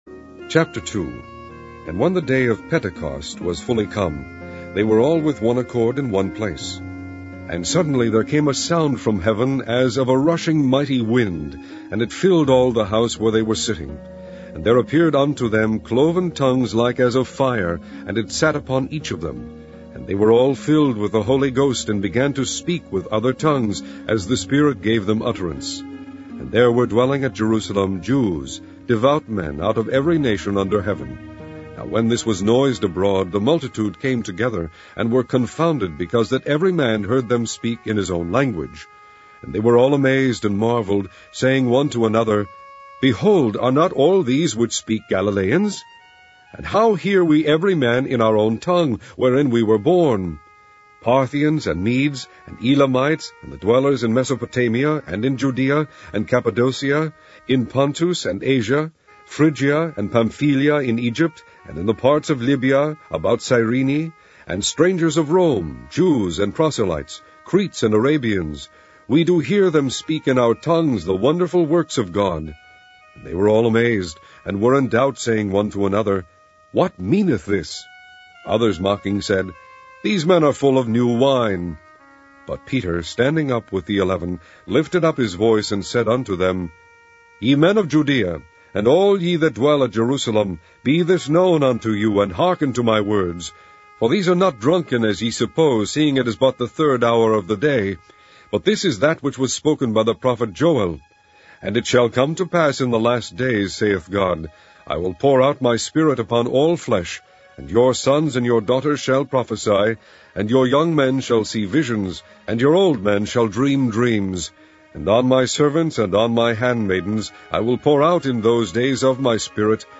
King James Audio Bible - mp3's